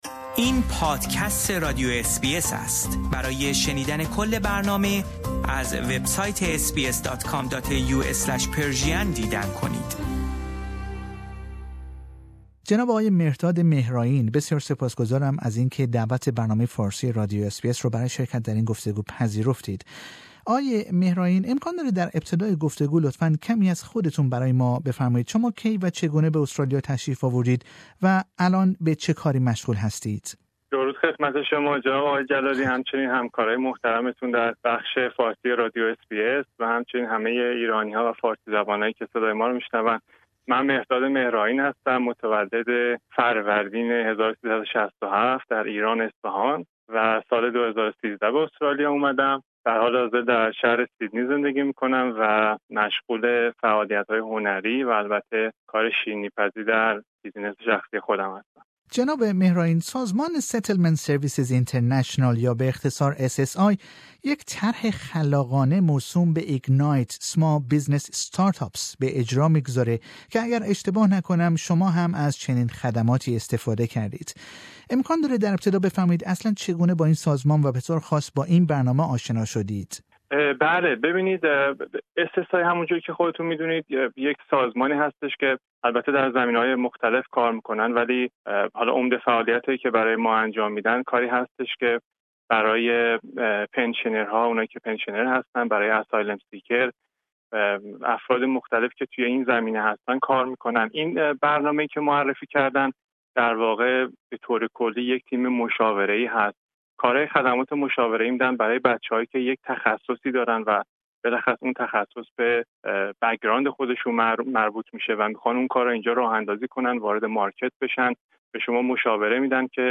This interview is not available in English.